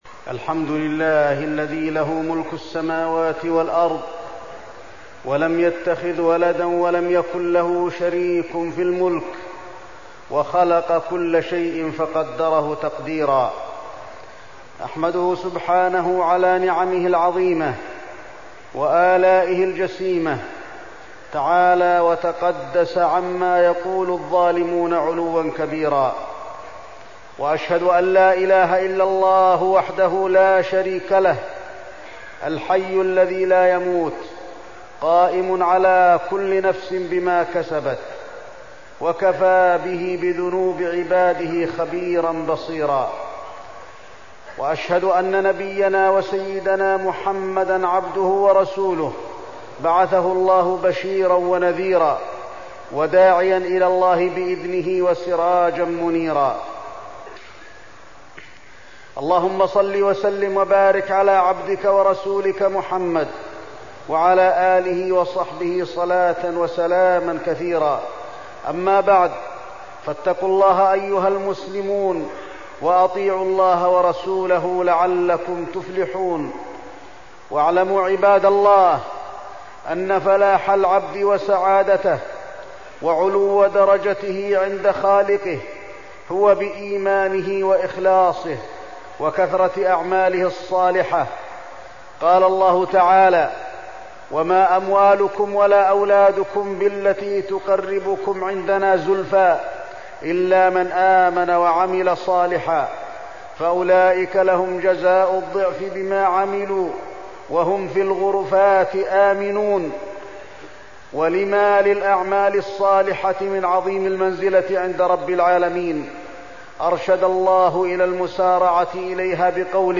تاريخ النشر ٥ جمادى الأولى ١٤١٦ هـ المكان: المسجد النبوي الشيخ: فضيلة الشيخ د. علي بن عبدالرحمن الحذيفي فضيلة الشيخ د. علي بن عبدالرحمن الحذيفي المسارعة إلى الخيرات